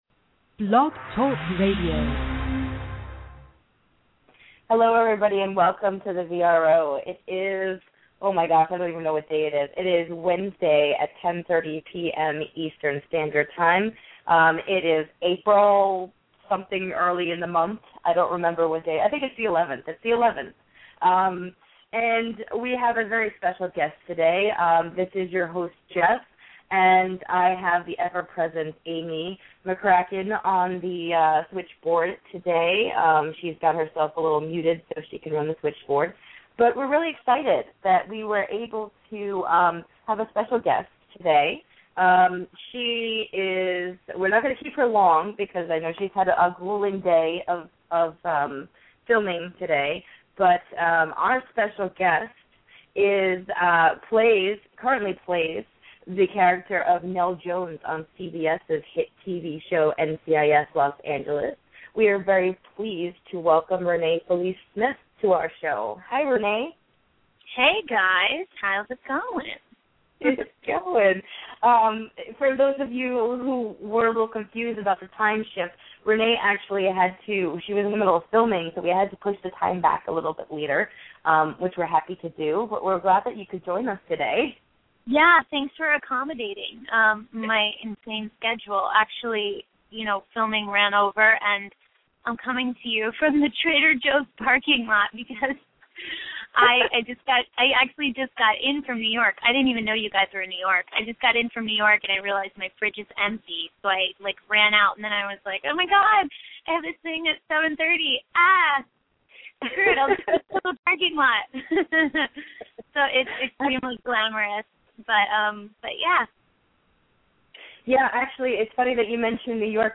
Renée Felice Smith Interview